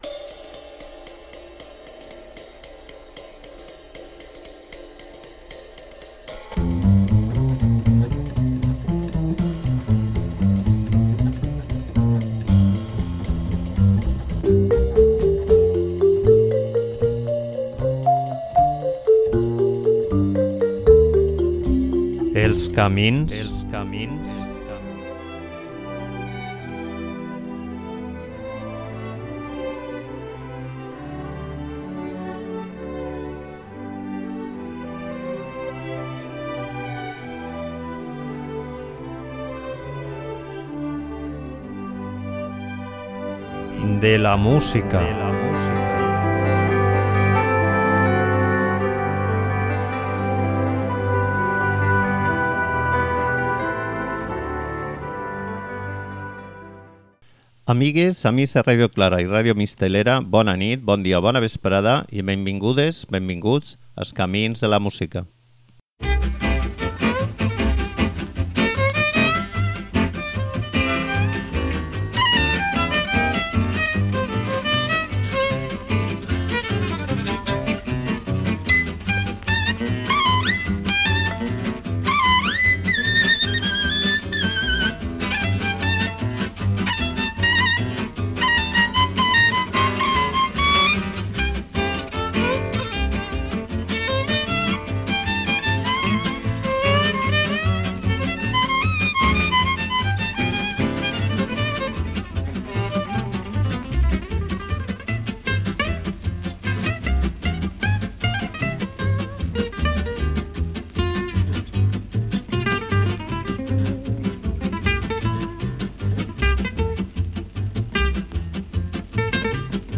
Pianista i, sobretot, violinista, Grapelli ha mantingut intacta la seua tècnica i el seu swing impecable i elegant al llarg de tota la seua carrera, que és com dir tota la seua vida, car es va morir tocant fins l'últim dia com qui diu.